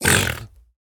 Minecraft Version Minecraft Version 25w18a Latest Release | Latest Snapshot 25w18a / assets / minecraft / sounds / mob / piglin_brute / hurt1.ogg Compare With Compare With Latest Release | Latest Snapshot